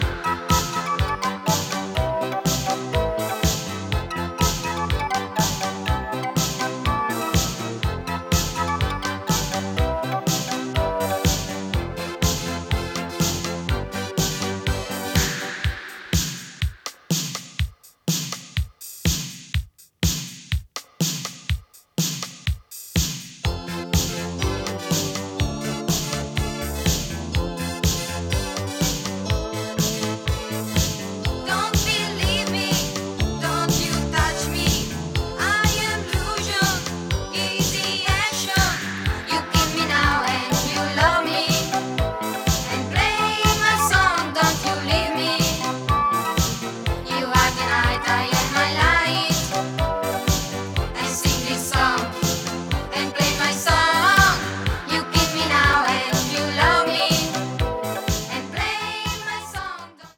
最高なイタロ・ディスコ秘宝です。